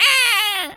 seagul_squawk_hurt_high1.wav